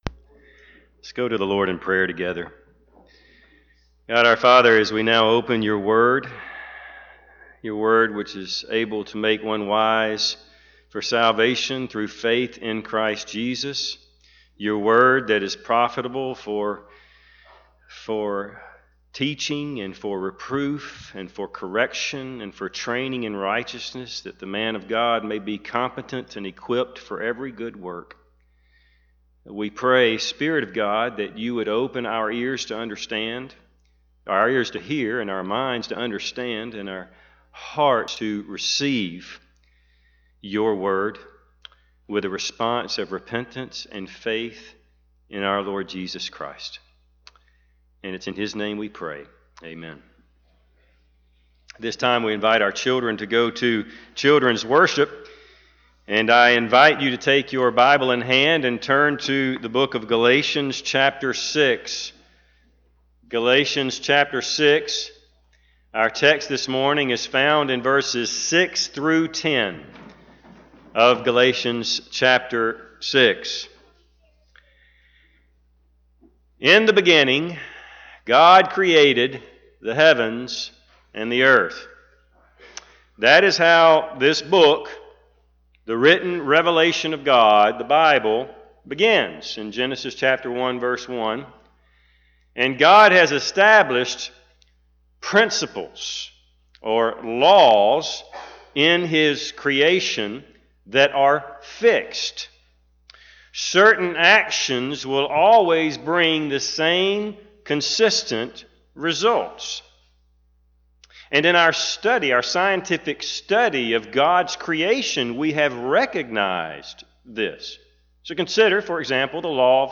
Galatians 6:6-10 Service Type: Sunday AM Bible Text